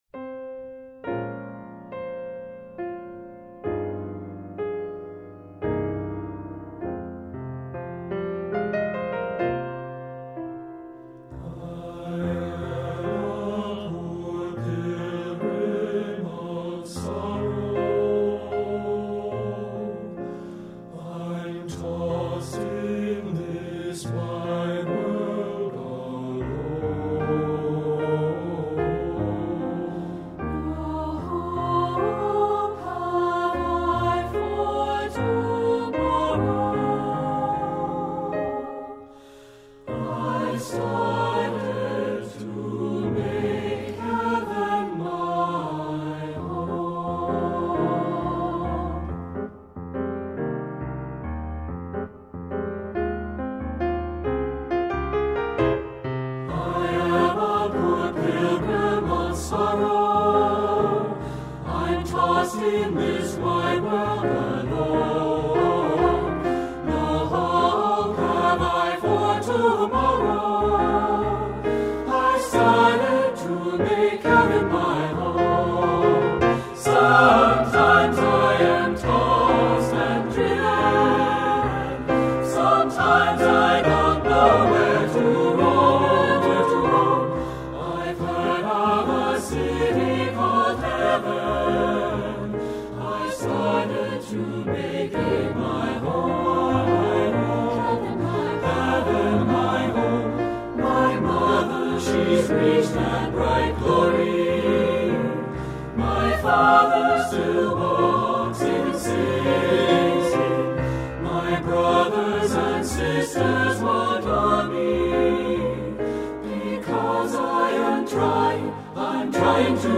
Voicing: TTB/TBB